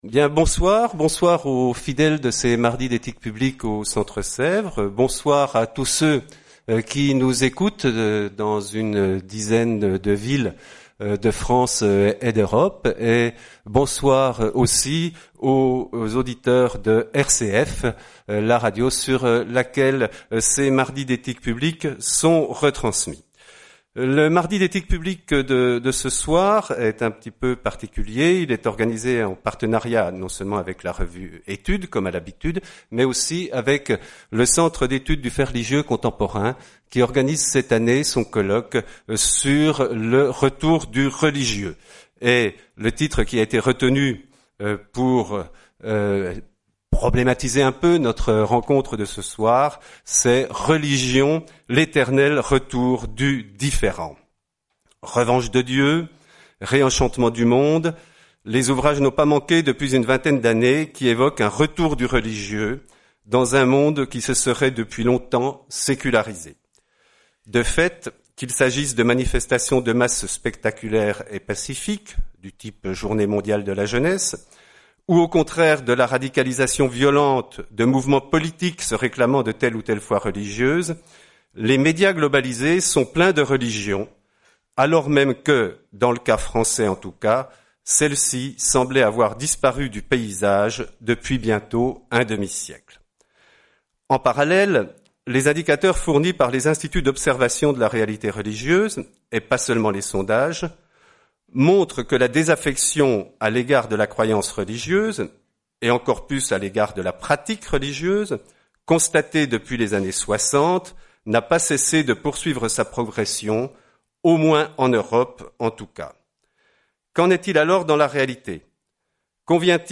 Soirée Mardi d'éthique publique du 3 avril 2018, en partenariat avec le Cefrelco, la revue Études et RCF.